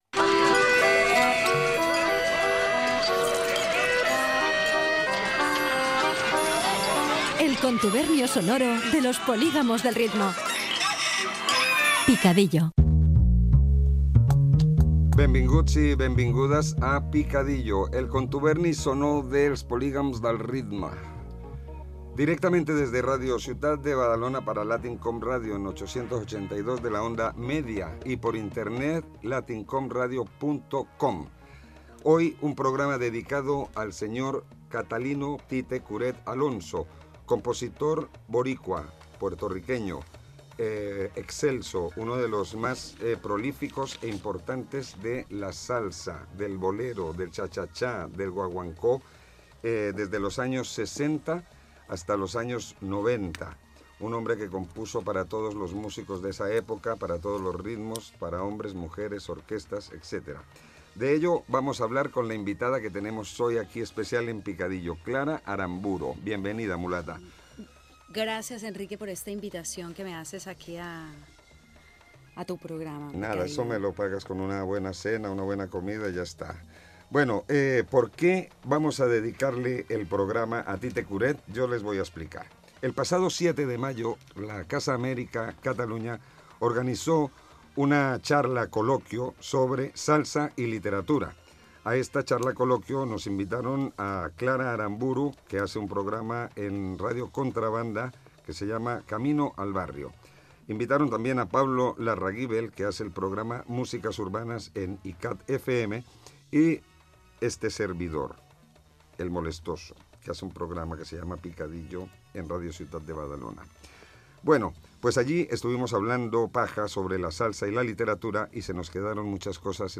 Gènere radiofònic Musical
Espai fet des dels estudis de Ràdio Ciutat de Badalona.